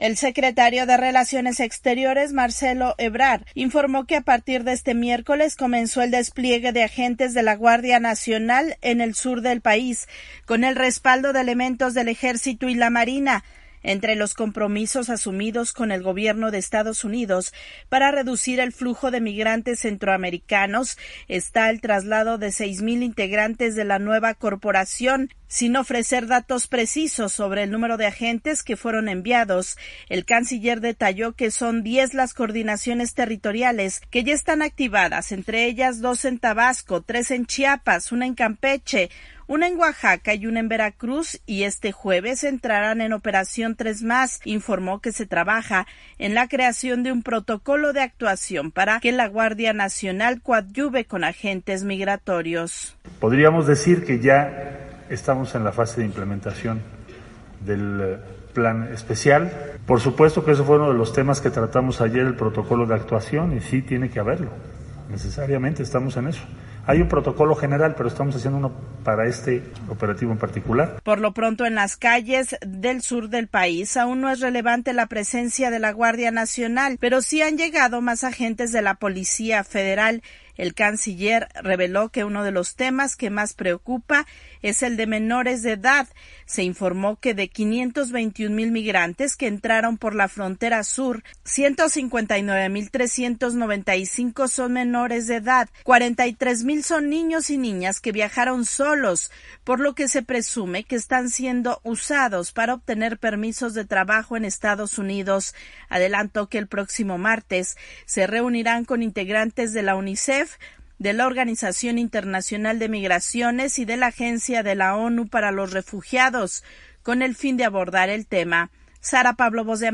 VOA: Informe desde México